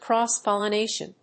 アクセントcróss‐pollinátion